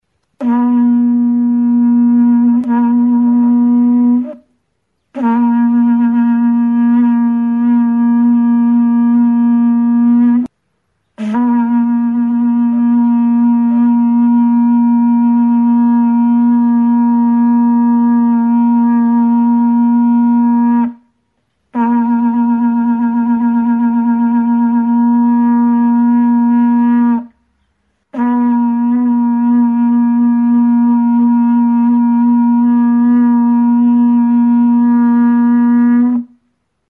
Aerófonos -> Vibración labios (trompeta) -> Naturales (con y sin agujeros)
Grabado con este instrumento.
Tronpeta gisako ahoa duen idi adarra da.